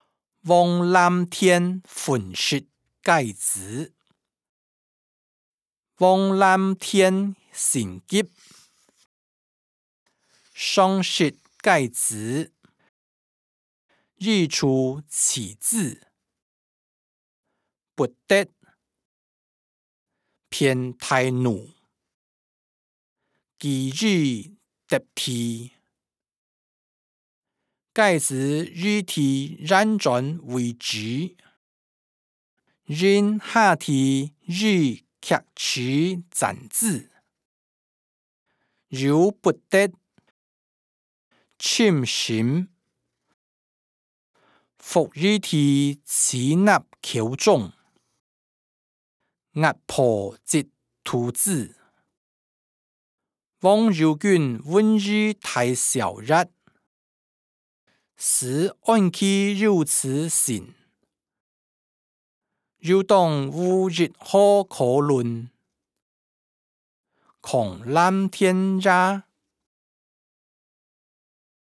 小說-王藍田忿食雞子音檔(海陸腔)